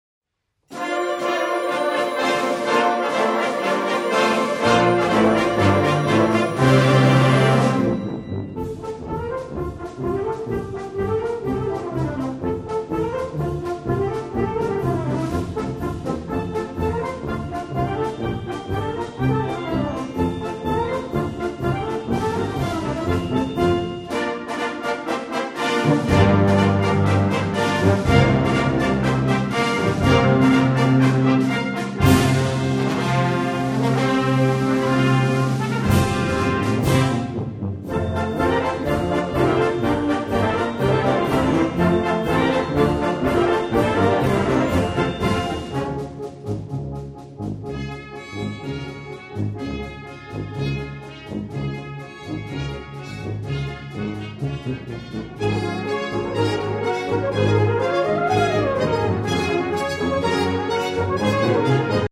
• Sheetmusic für Blasorchester, Fanfare und Brass Band